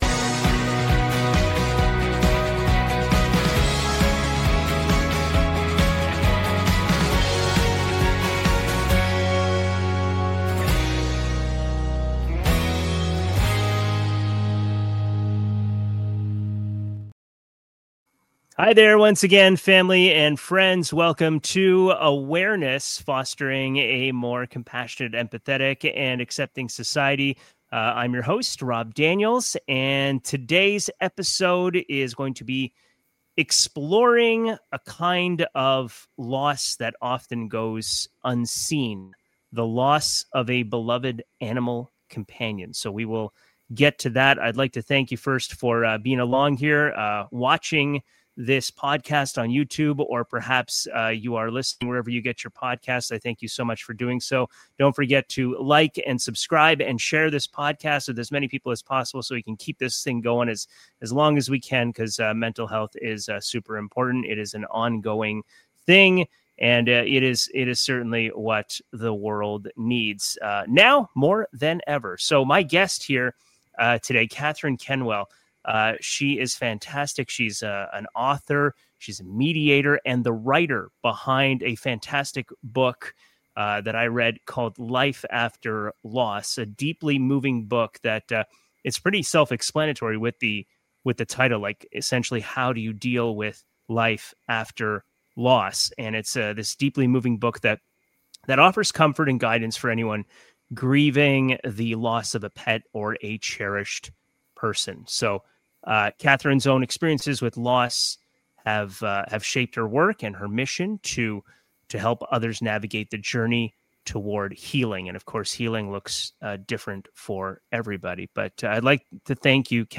This heartfelt conversation offers comfort, understanding, and practical guidance for anyone dealing with pet grief.